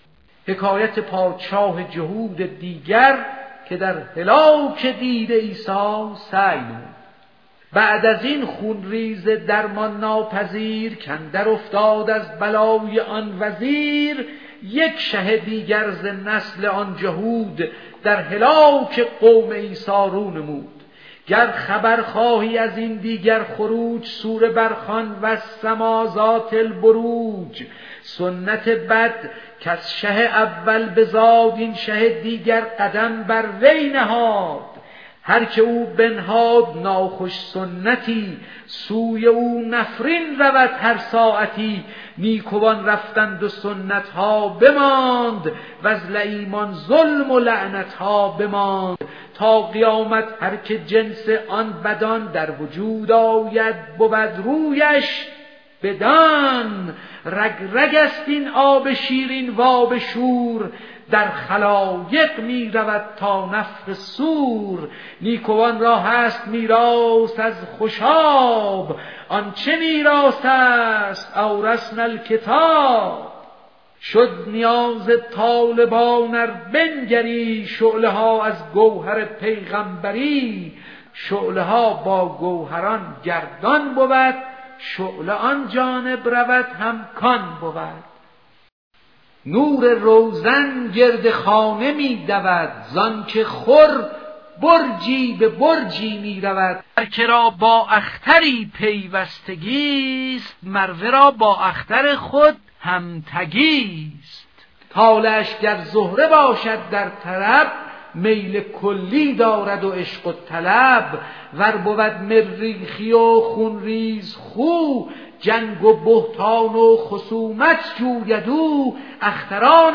دکلمه دشمنی پادشاه جهود دیگر با نصرانیان